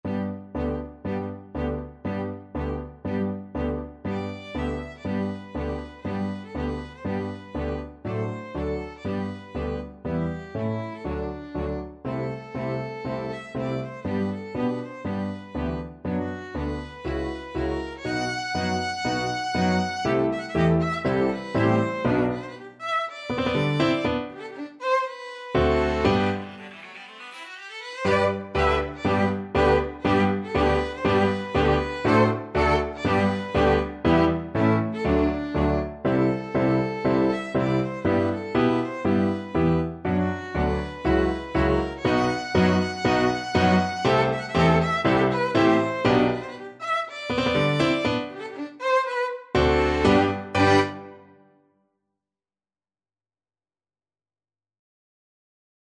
Violin 1Violin 2ViolaCelloPiano
2/4 (View more 2/4 Music)
Allegro (View more music marked Allegro)
Piano Quintet  (View more Intermediate Piano Quintet Music)
Classical (View more Classical Piano Quintet Music)